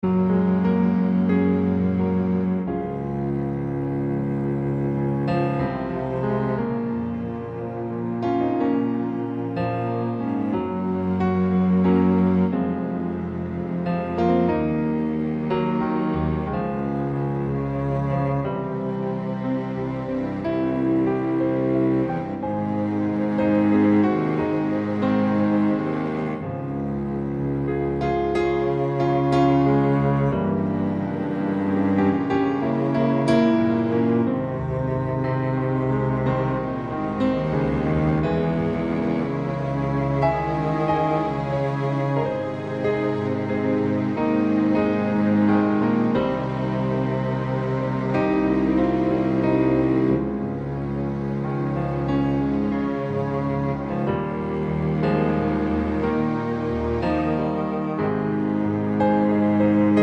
High Female Key